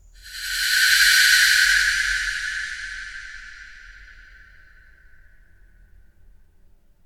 ホラー（心理）
霊が通り過ぎる
ghost_passed.mp3